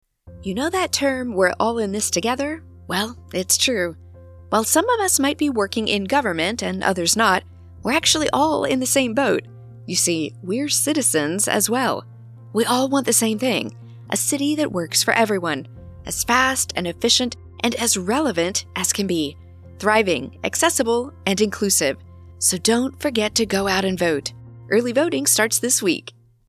Genuine, Authentic, Real Person
General American
Middle Aged
DEMO--Phone Prompt Friendly Conversational Empathetic W Music.mp3